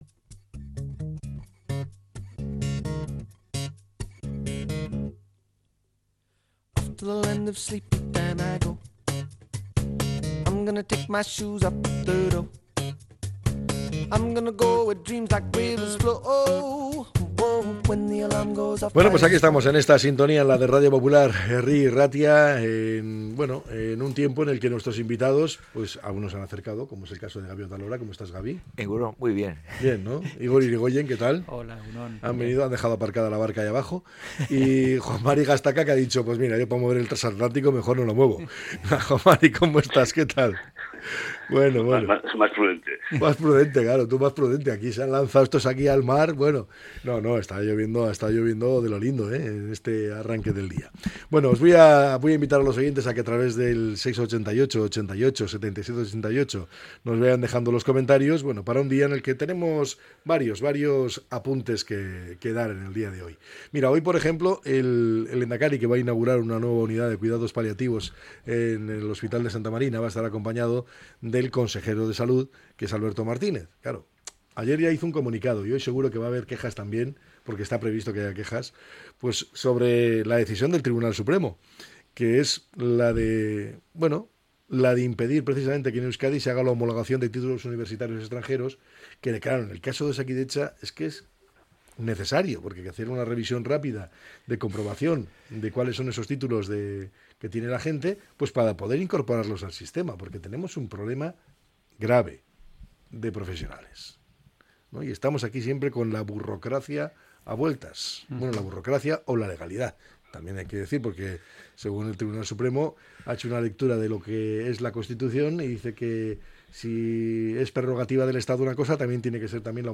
La tertulia 24-03-25.